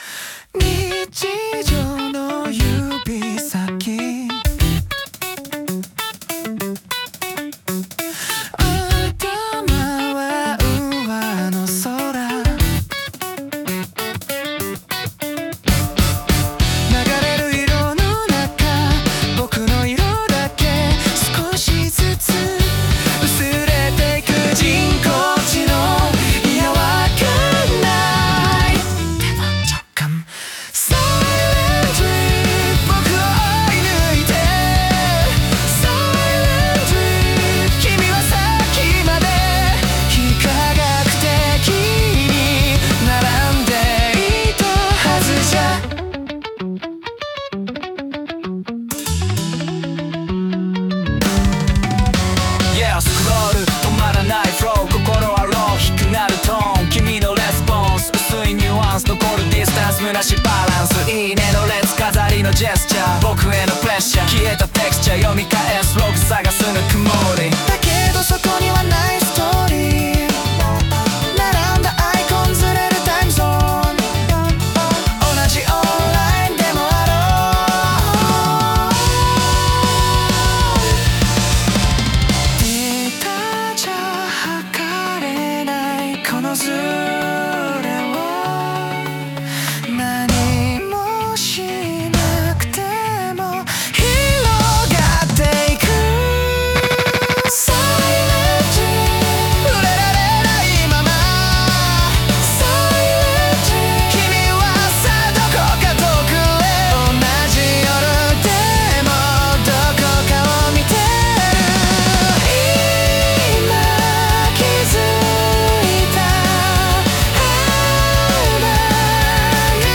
男性ボーカル
イメージ：J-ROCK,ラップパート,男性ボーカル,かっこいい,切ない